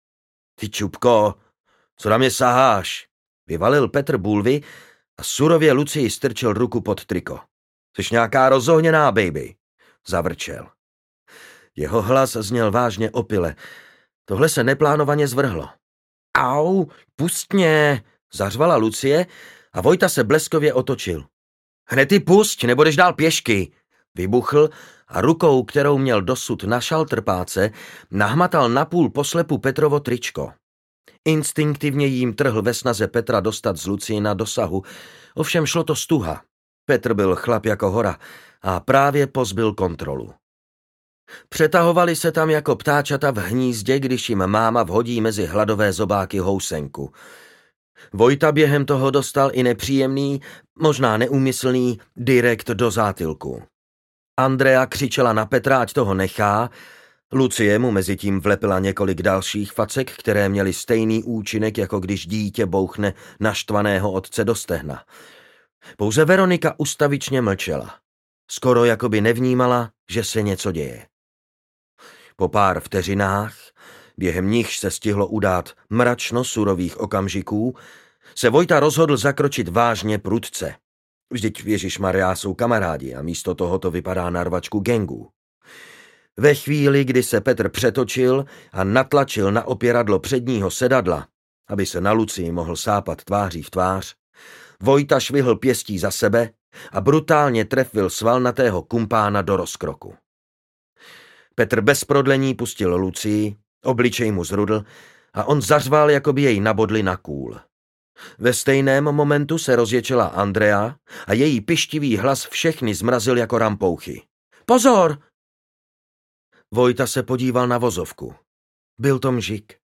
Les audiokniha
Ukázka z knihy
Vyrobilo studio Soundguru.